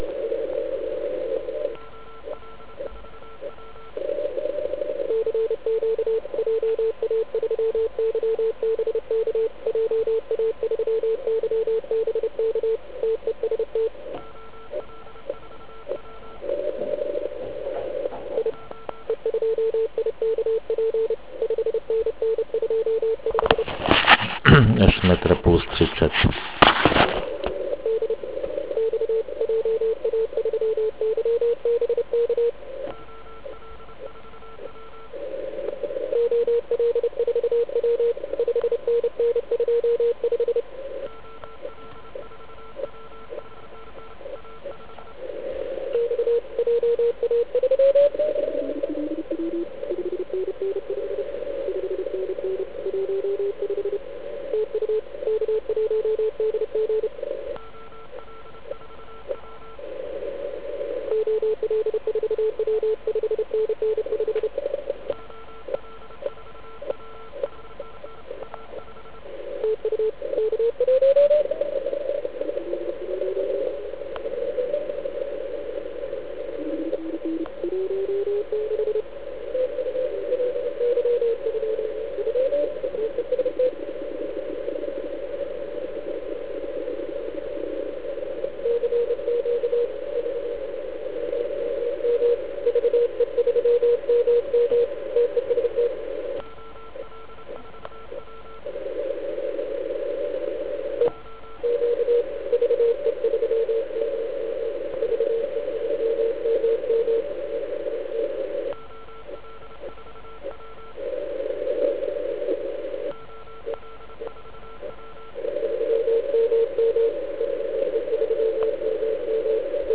Zkrátka typická Ionoduktí spojení. Moje pádlovka si šušňá.